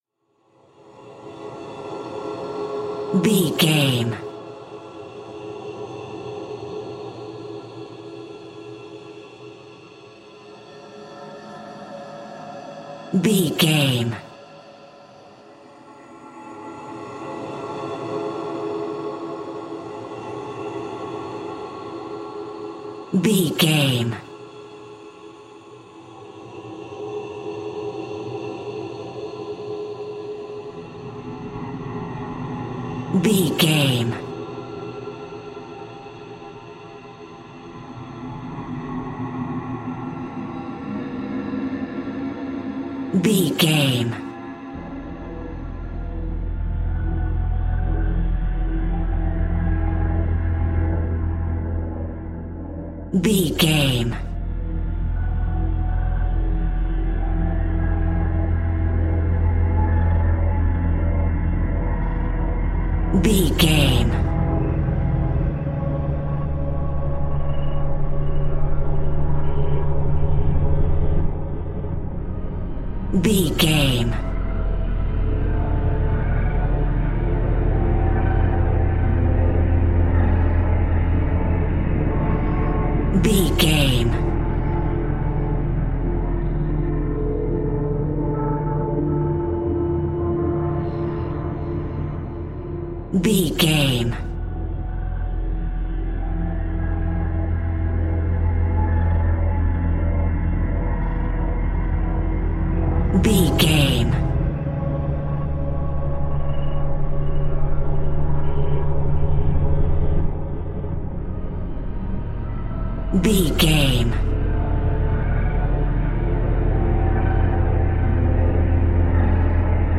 Thriller
Atonal
tension
ominous
dark
eerie
horror music
Horror Pads
horror piano
Horror Synths